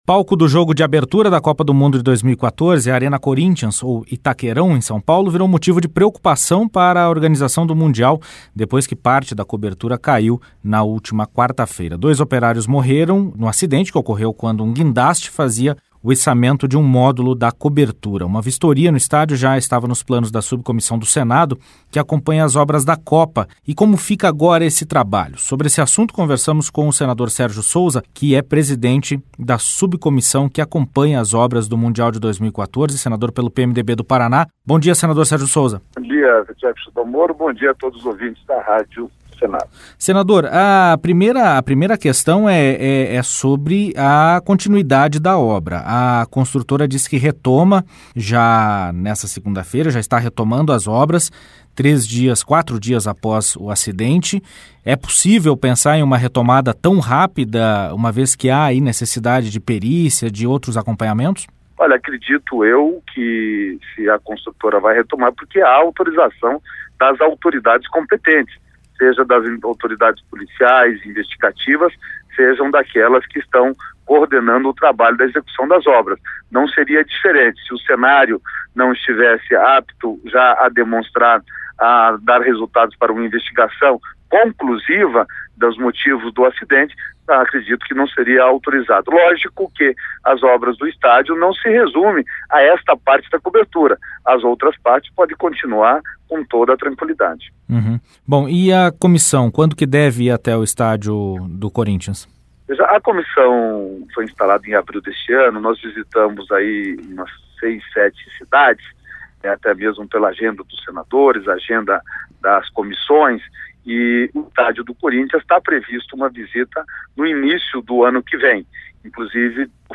Entrevista com o presidente da Subcomissão de Acompanhamento da Copa, senador Sergio Souza (PMDB-PR).